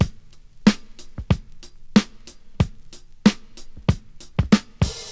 • 94 Bpm Drum Loop D Key.wav
Free drum groove - kick tuned to the D note.
94-bpm-drum-loop-d-key-qlx.wav